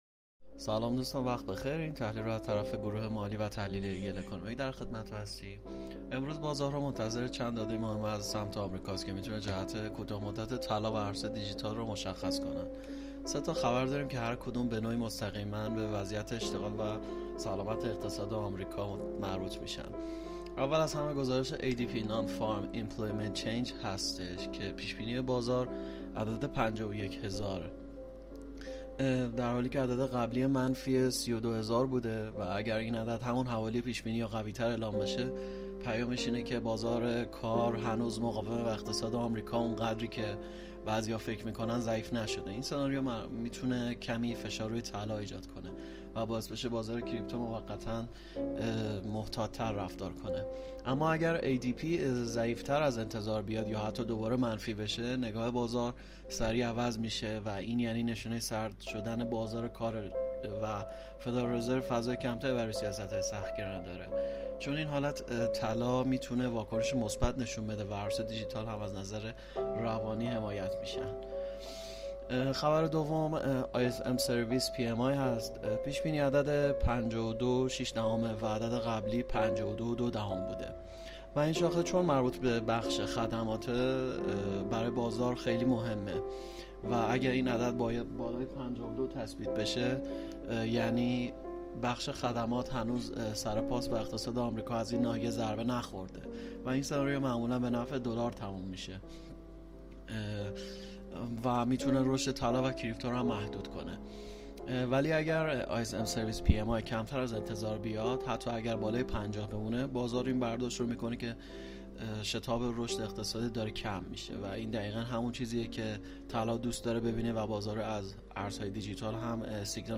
🔸گروه مالی و تحلیلی ایگل با تحلیل‌های صوتی روزانه در خدمت شماست!